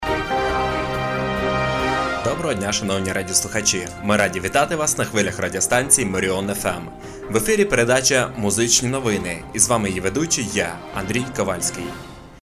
Тракт: Студийный микрофон Marshall MXL990, аудио интерфейс ALESIS IO2 EXPRESS , электро пианино-синтезатор Сasio CDP-200.